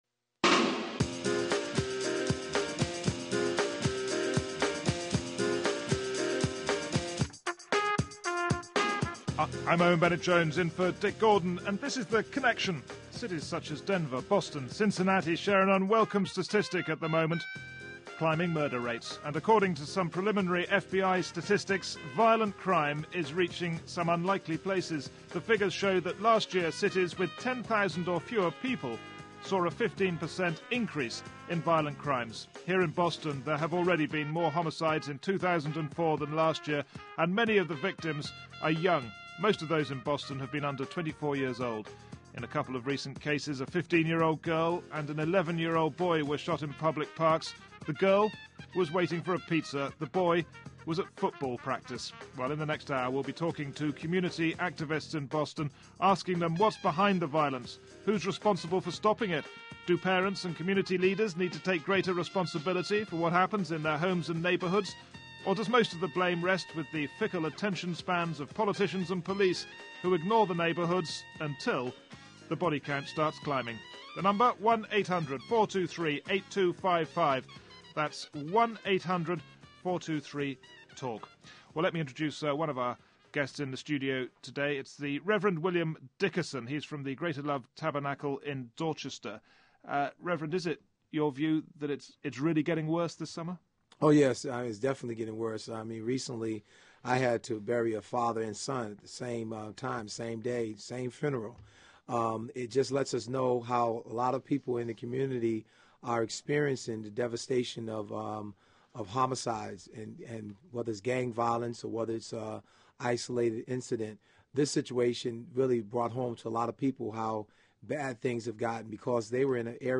Bill LaFortune, Mayor of Tulsa, OK